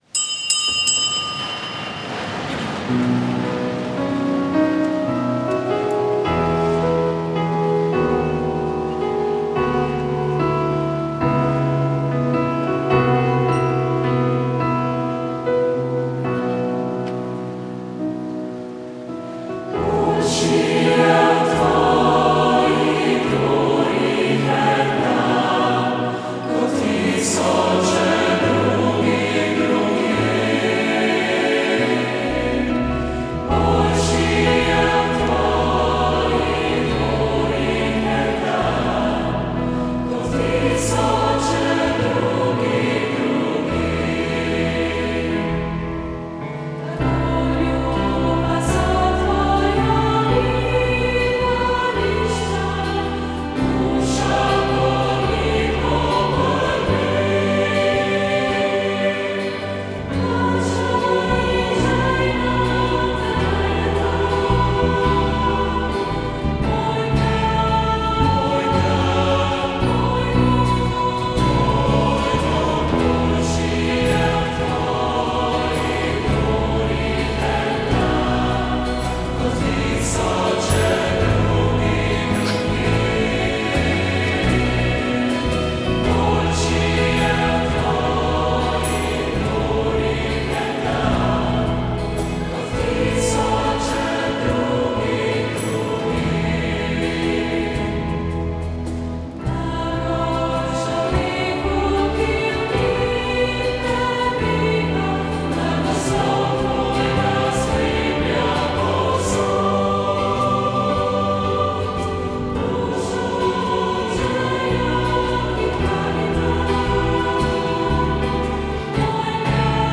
Sveta maša
Nedeljska sveta maša iz župnije Sv. Jurij
Iz župnije Sveti Jurij v Slovenskih goricah smo na 17. nedeljo med letom neposredno prenašali sveto mašo, pri kateri so sodelovali tamkajšnji verniki.
Za prenos je poskrbela ekipa Radia Maribor.